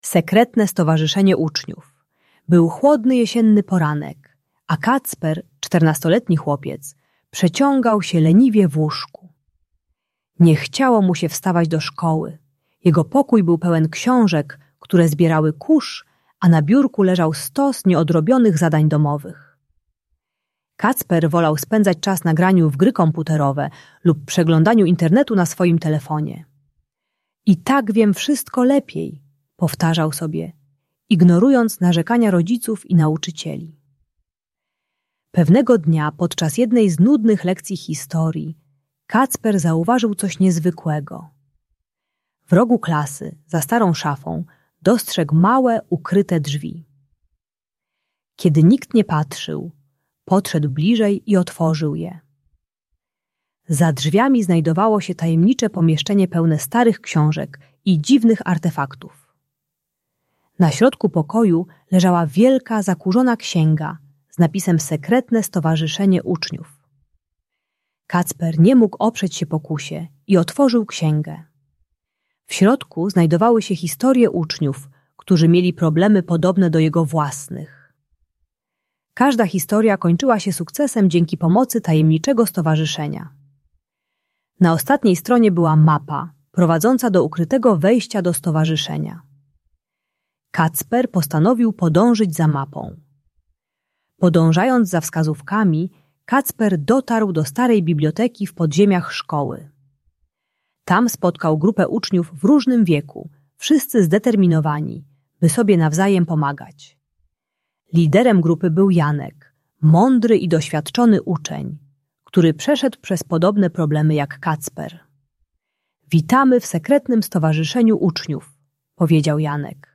Sekretne Stowarzyszenie Uczniów - Szkoła | Audiobajka